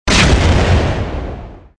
impact_missile.wav